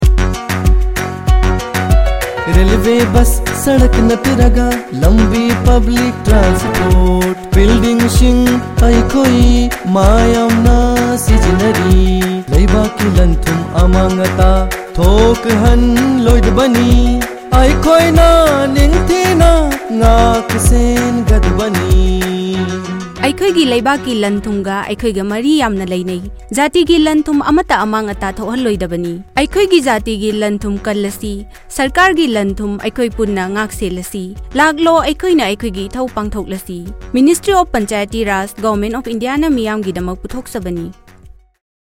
143 Fundamental Duty 9th Fundamental Duty Safeguard public property Radio Jingle Manipuri